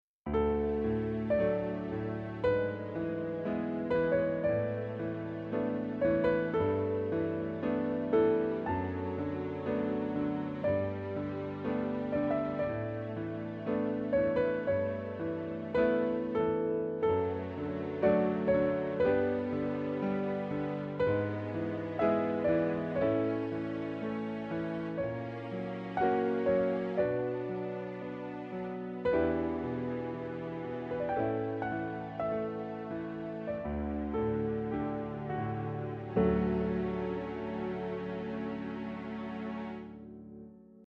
ゆるやかなひと 時